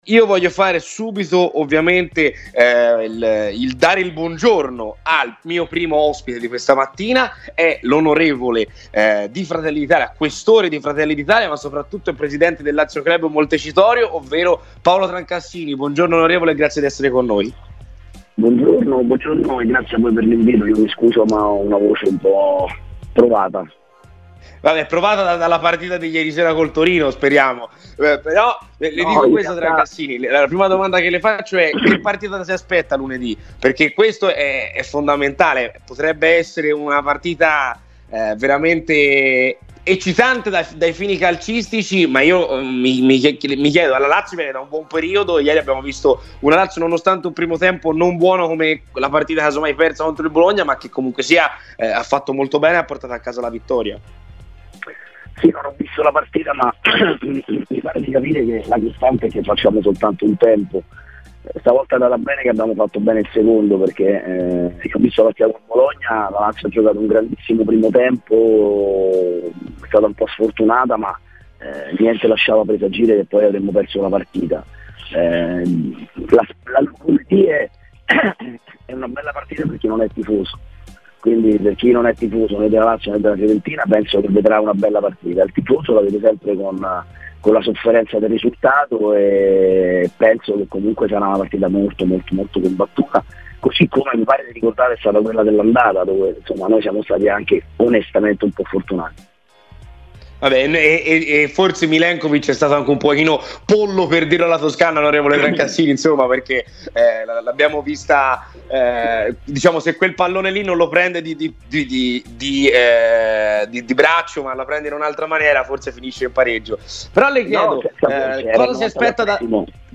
In diretta a "C'è Polemica" su Radio Firenzeviola, il deputato per Fratelli d'Italia e fondatore del Lazio Club Montecitorio Paolo Trancassini ha parlato così: "La costante della Lazio è che fa un solo tempo, ieri è andata bene che è stato il secondo.
Paolo Trancassini a Radio Firenze Viola